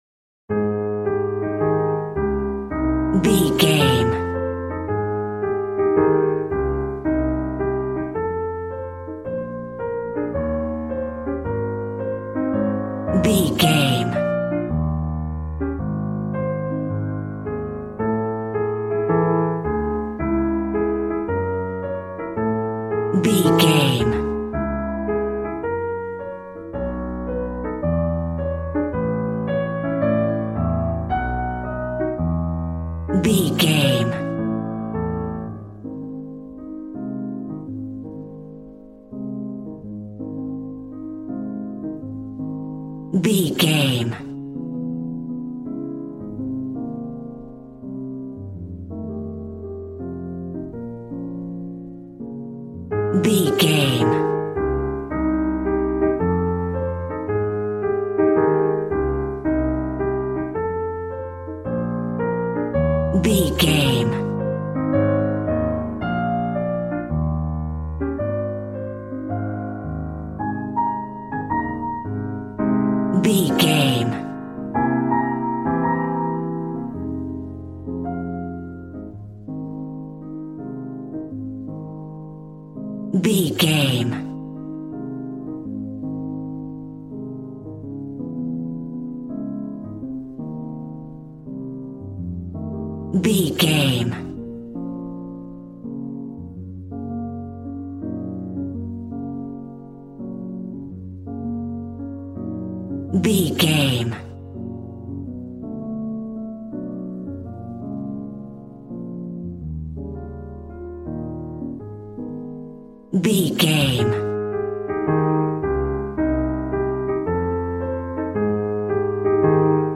Ionian/Major
A♭
smooth
drums